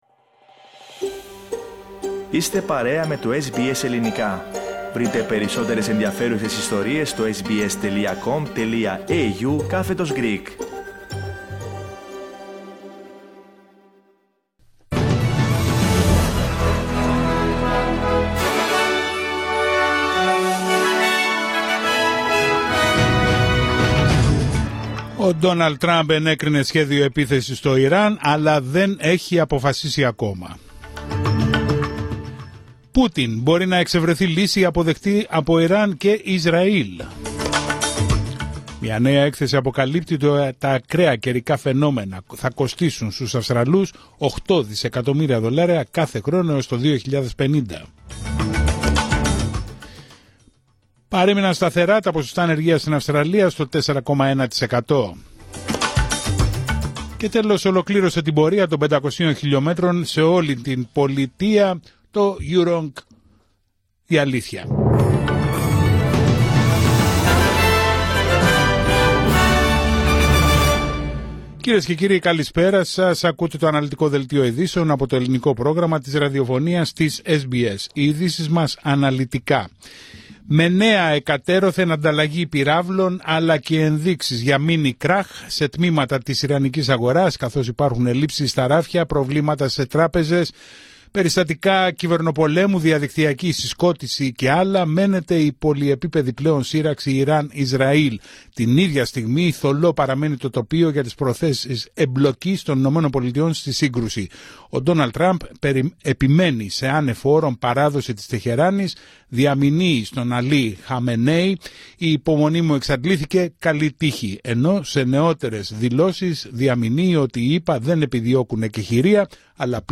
Δελτίο ειδήσεων Πέμπτη 19 Ιουνίου 2025